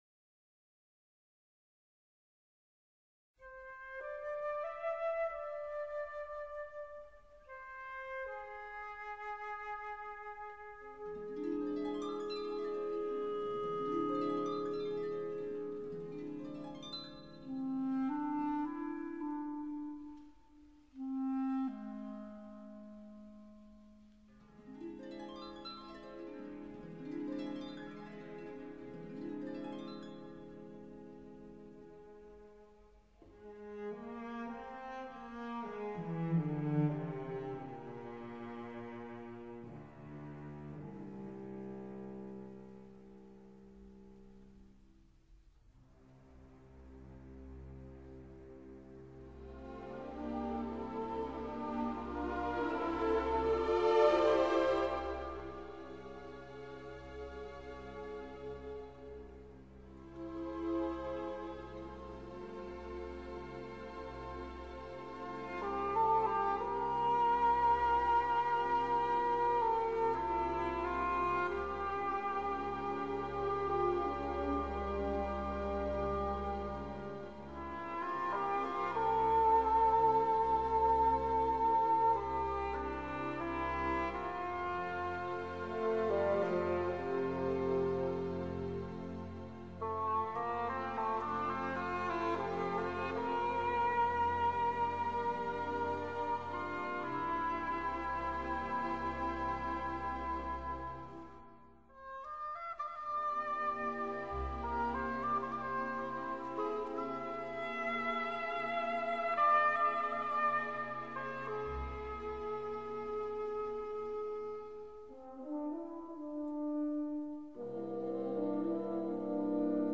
valzer lento
colonna sonora